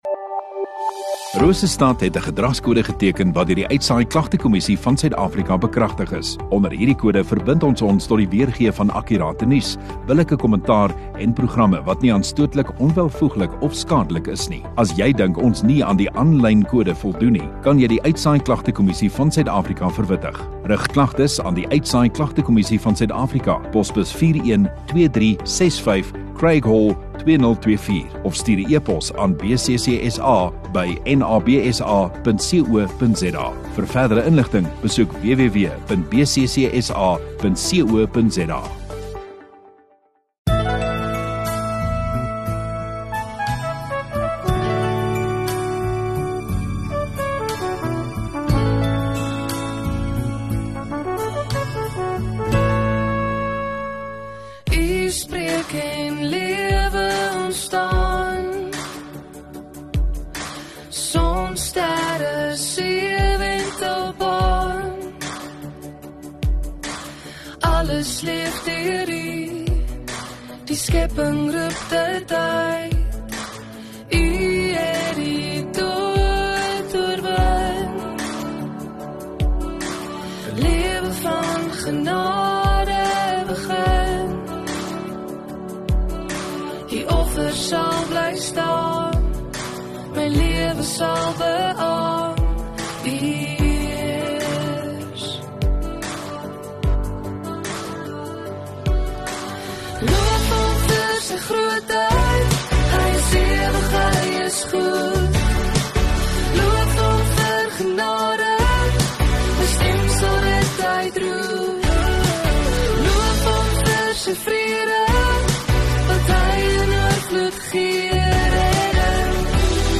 24 Nov Sondagaand Erediens